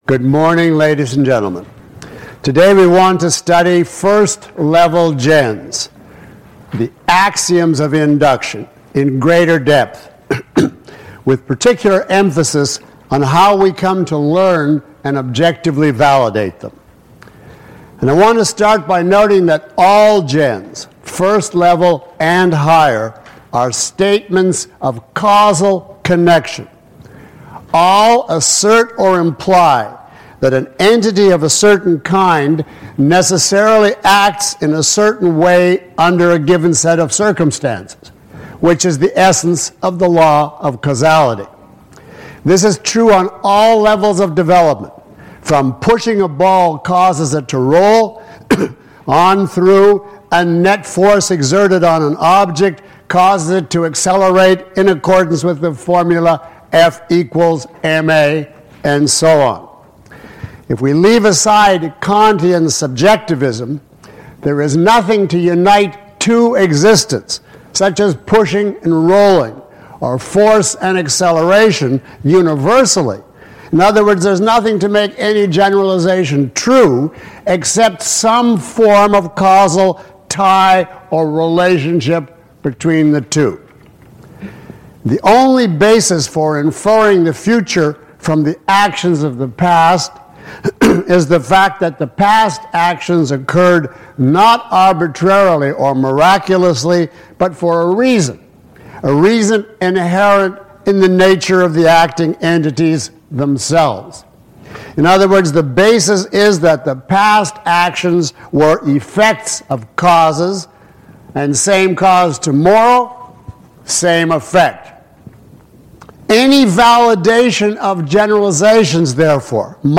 Lecture 02 - Induction in Physics and Philosophy.mp3